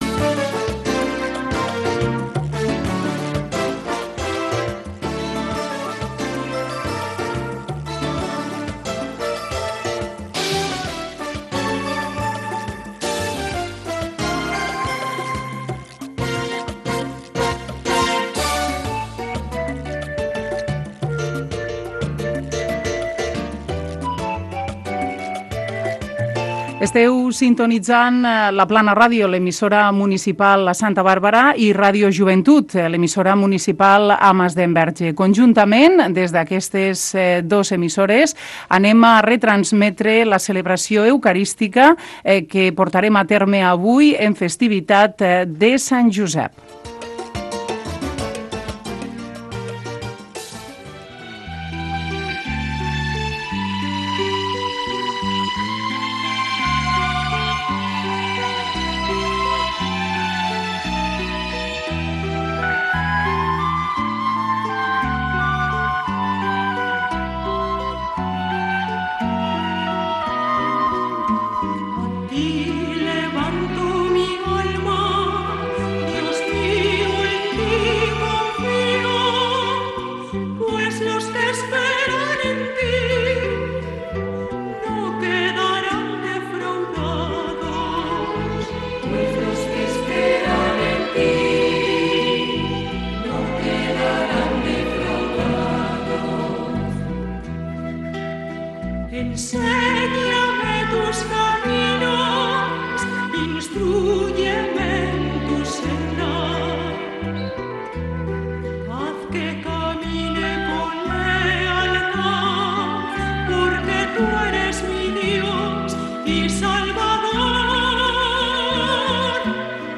Religió
Transmissió feta per La Plana Ràdio, de Santa Bàrbara.